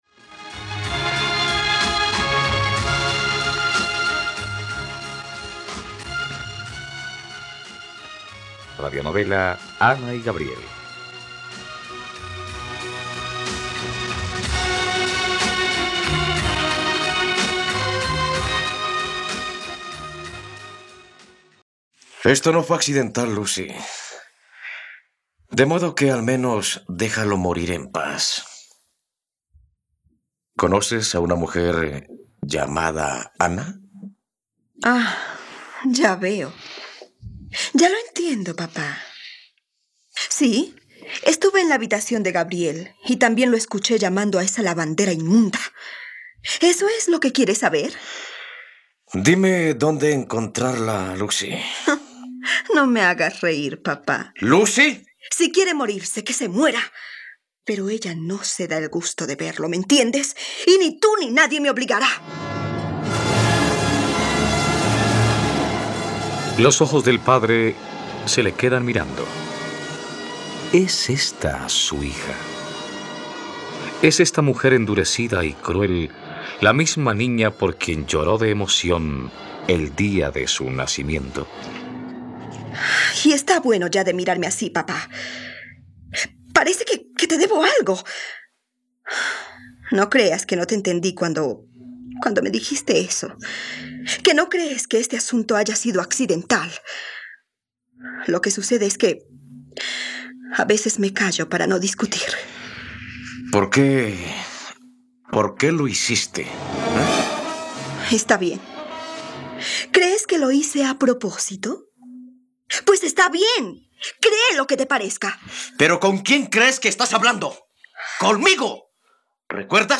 Ana y Gabriel - Radionovela, capítulo 102 | RTVCPlay